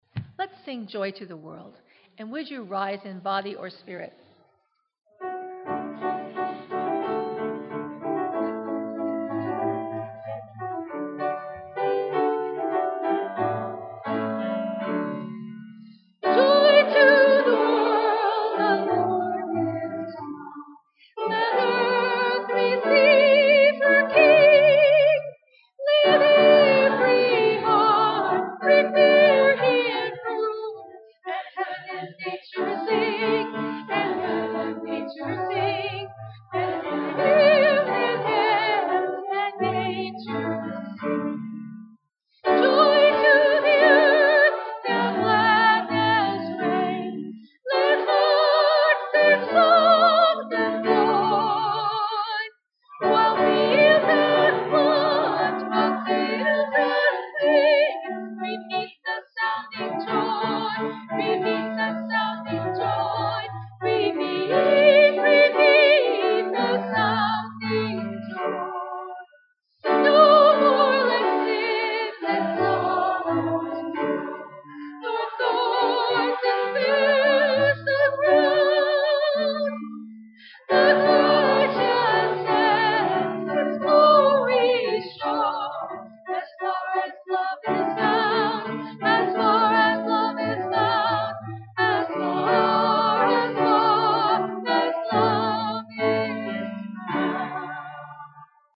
Song: Joy to the World